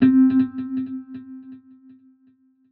Guitar - Tiny.wav